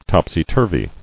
[top·sy-tur·vy]